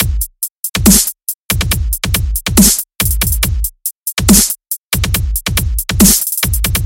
（介绍鼓填充）在我的手机上编辑，所以质量有点可怕，但可听。
这是meh但我在录制时却坐在一个无聊的停车场。
标签： 循环 均衡器 电子 DJ 科林斯 压缩 节拍 BPM 菲尔楼 鼓楼
声道立体声